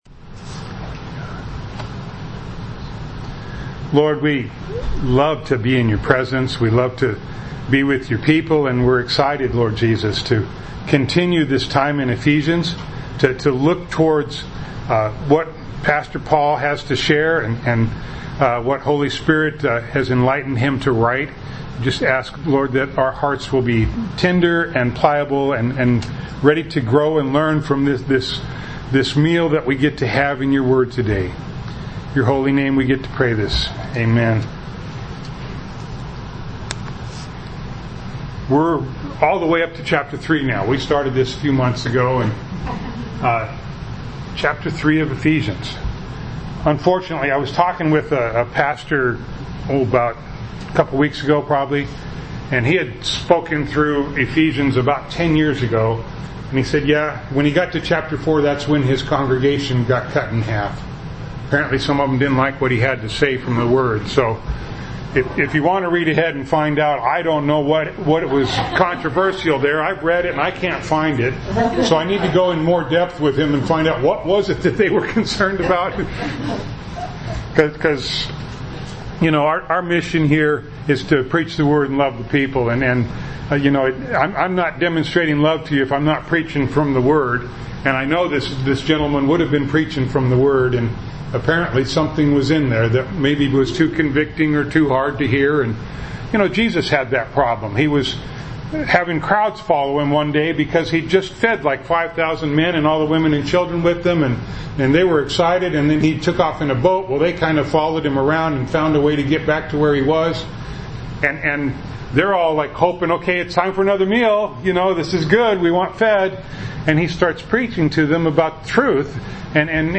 Ephesians 3:1 Service Type: Sunday Morning Bible Text